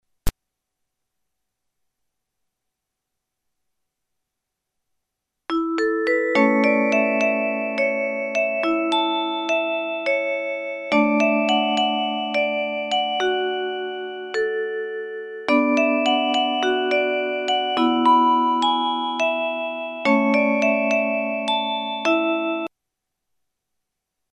- Suite for 18 note music box mechanism